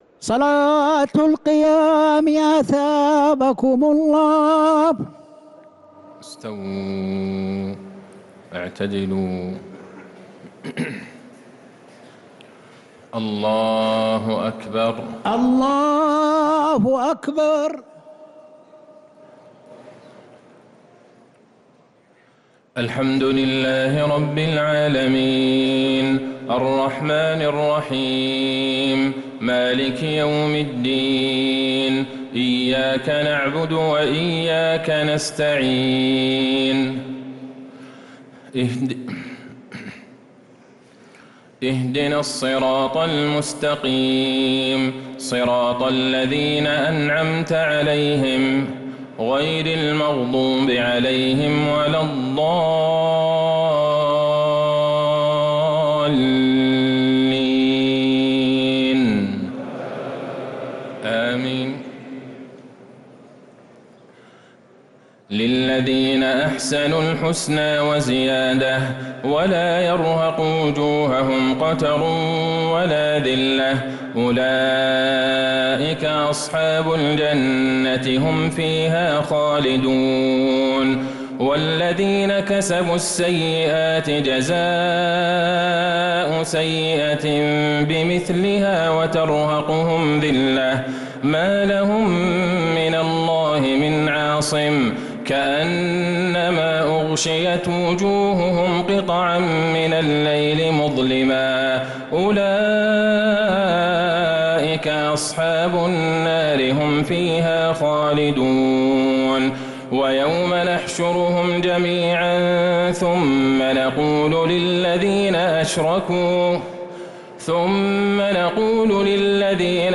تراويح ليلة 15 رمضان 1446هـ من سورة يونس {26-109} | Taraweeh 15th Ramadan niqht 1446H Surat Yunus > تراويح الحرم النبوي عام 1446 🕌 > التراويح - تلاوات الحرمين